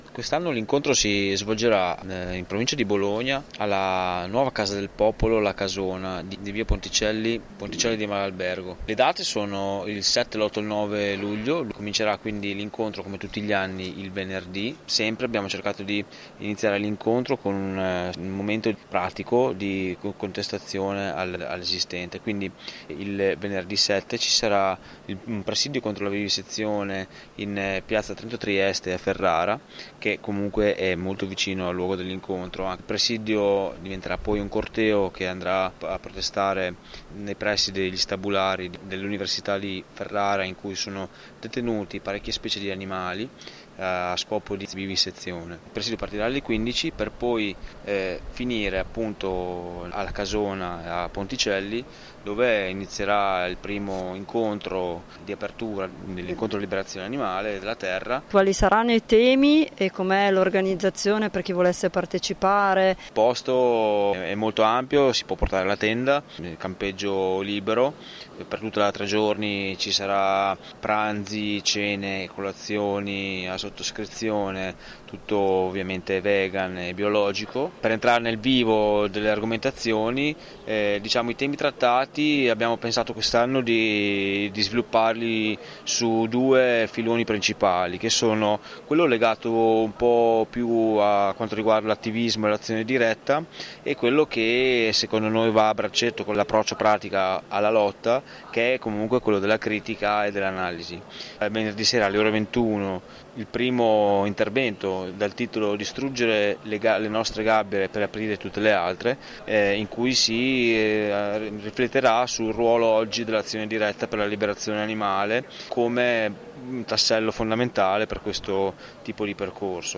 Il 13° Incontro per la liberazione animale e della terra / INTERVISTA